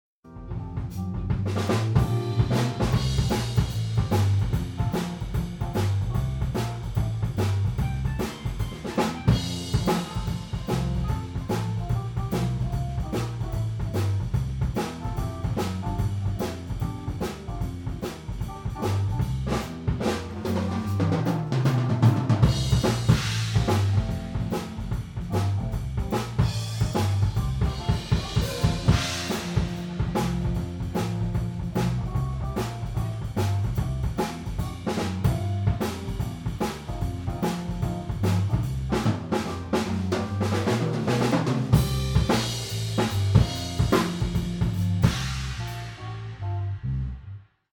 Live-Mitschnitt bei Kneipengig - Bericht mit Audiobeispielen
2x Raum/Publikum (Sennh. MKH40)
Hier die Soundbeispiele - die Einzelsignale sind roh und unbearbeitet - die Mischung natürlich schon...
Raum-Mikrofone
Room_MKH40_Mini-AB.MP3